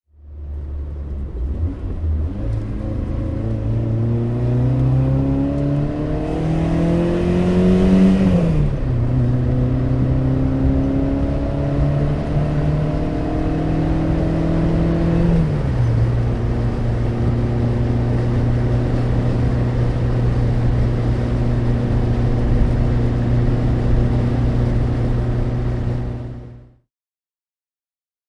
NSU TT (1971) - Fahrgeräusch (innen)
NSU_TT_1971_-_Fahrgeraeusch_innen.mp3